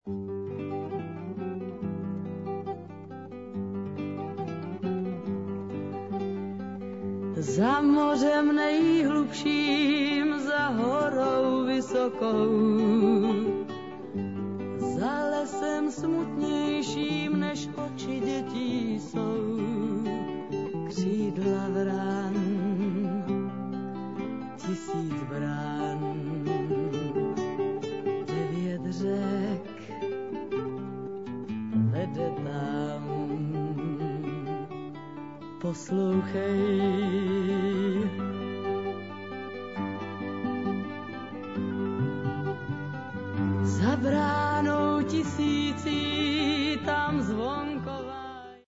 In the late 60's the most popular Czech woman-singer